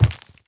troll_archer_walk_right.wav